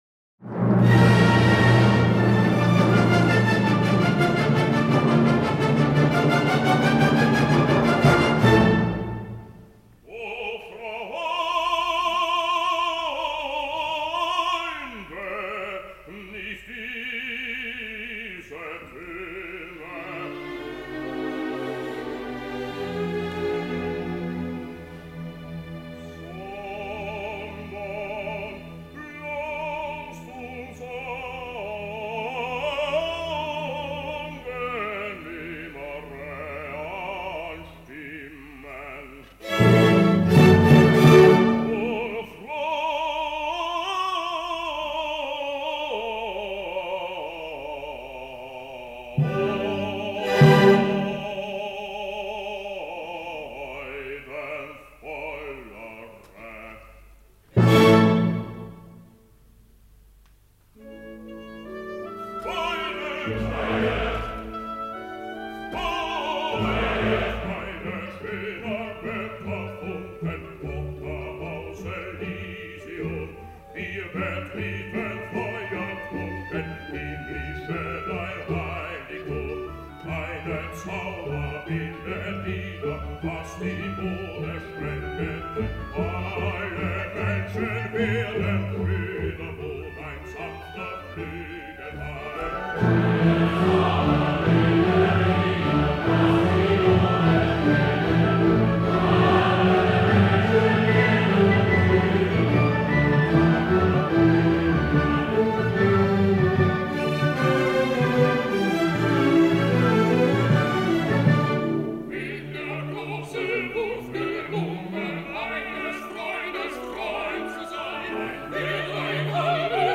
5. OTTO EDELMANN (Bass)
Bayreuth Festival Orchestra
cond. by Wilhelm Furtwängler
(Recorded: July 1951, Live}